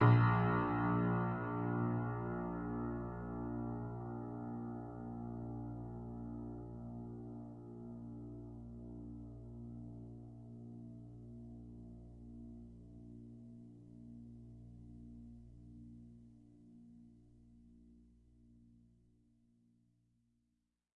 描述：记录了一架GerardAdam钢琴，它至少有50年没有被调音了！
Tag: 失谐 恐怖 踏板 钢琴 弦乐 维持